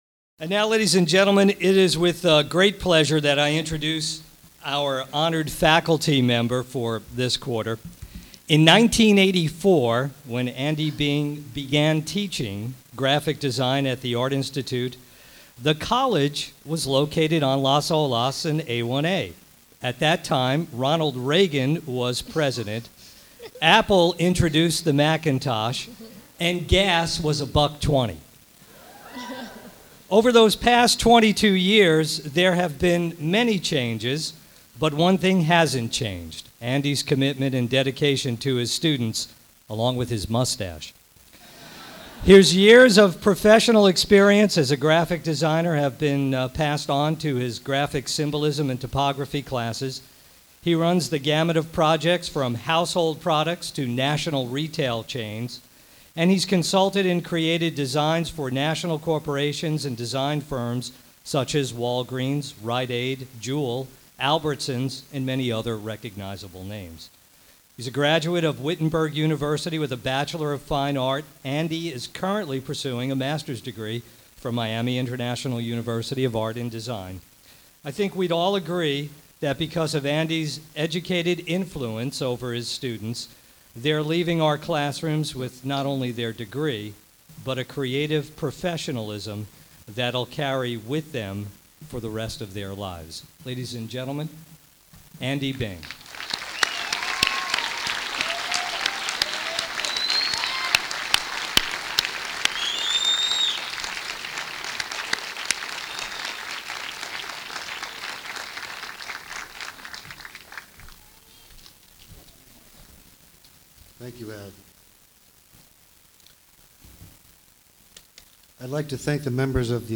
Graduation Speech
AIFL Graduation - September 2006 Loading time based on internet speed...